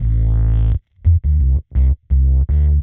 Index of /musicradar/dub-designer-samples/85bpm/Bass
DD_PBassFX_85A.wav